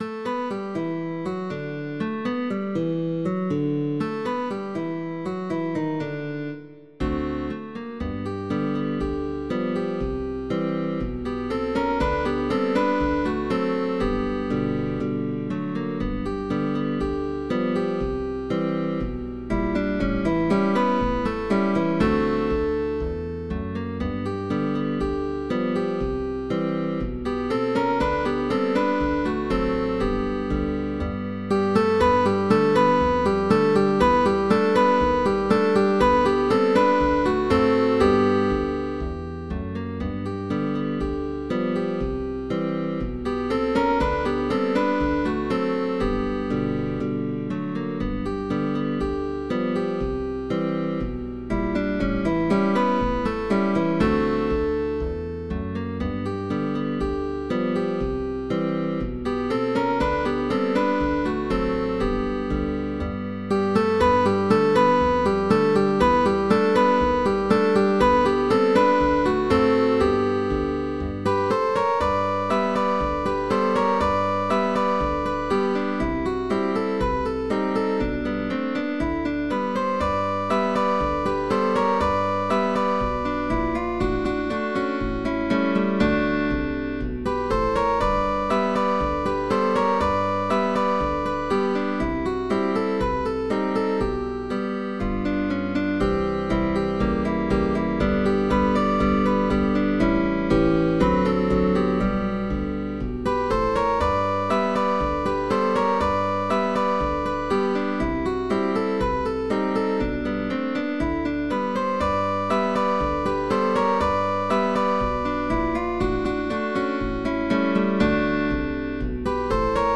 GUITAR QUARTET
Bass guitar optional.